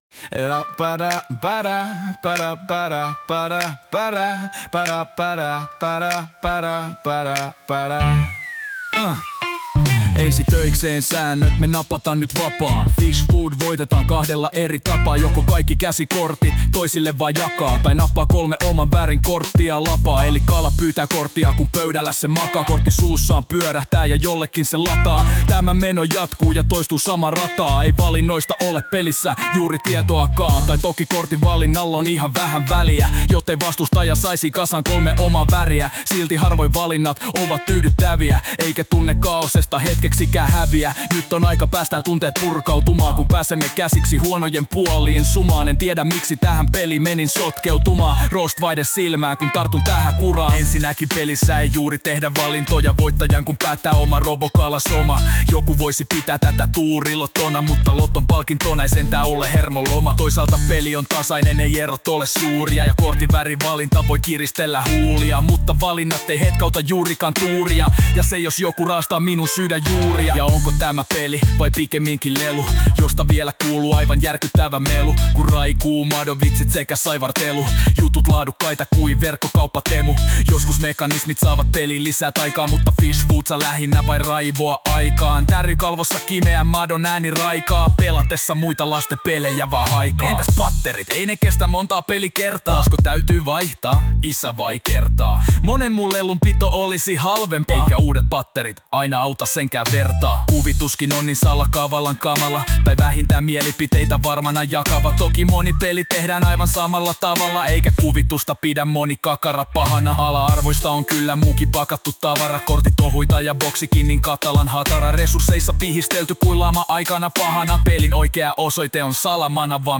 Niin biisi on tarjolla tekoälyn laulamana.